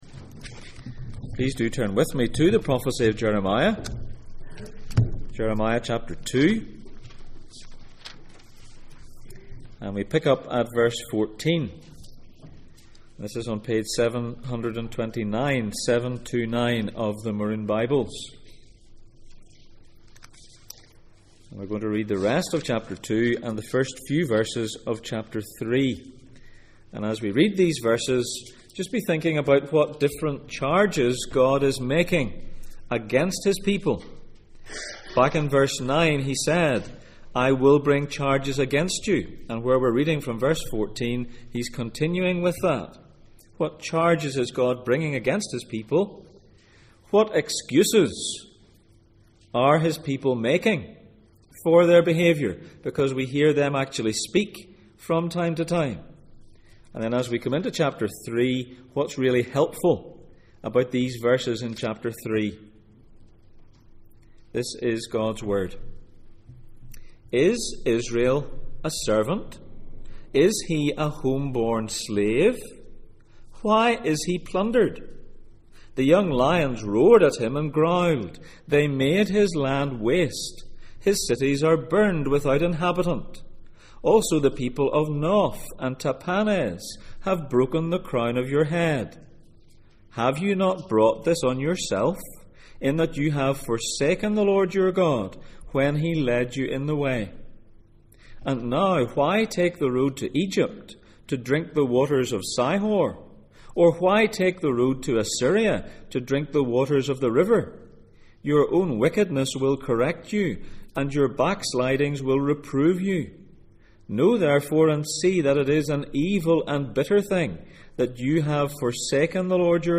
The highs and lows of Jeremiah Passage: Jeremiah 2:14-3:5 Service Type: Sunday Morning %todo_render% « Is the love gone?